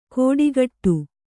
♪ kōḍigaṭṭu